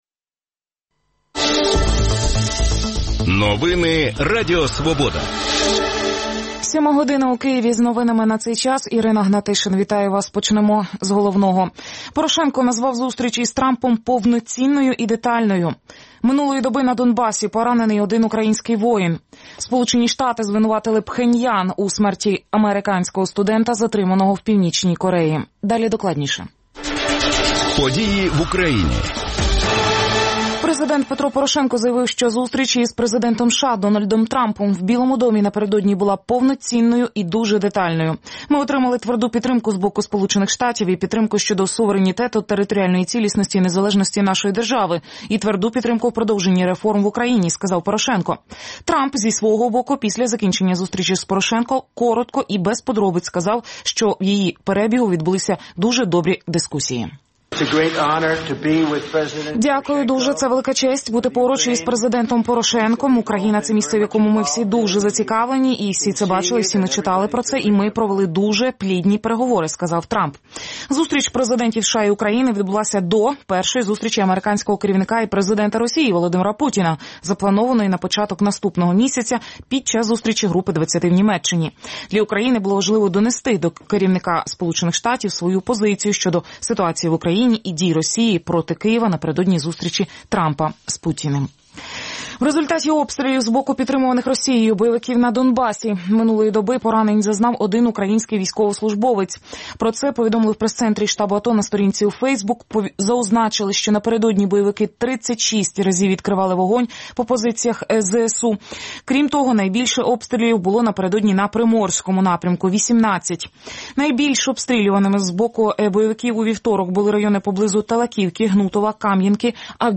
гості студії